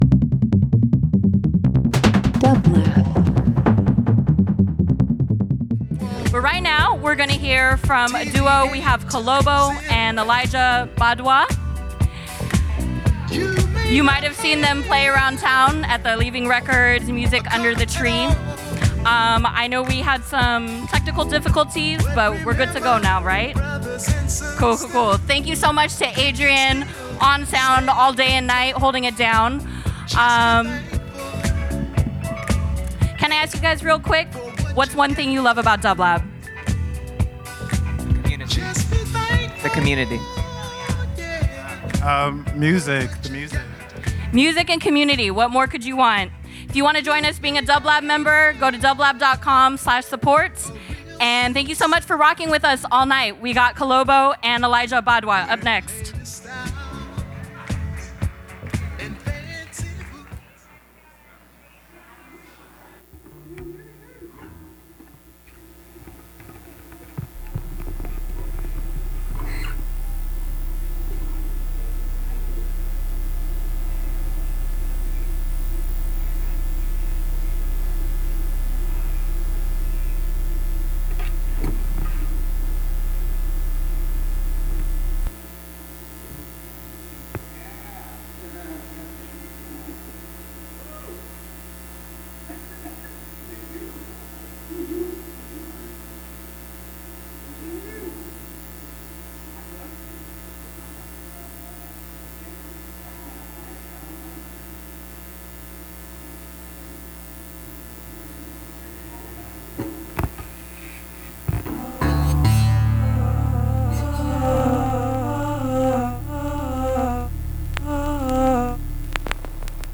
LIVE FROM THE LOFT @ PORTER STREET STUDIO – OCT 11, 2025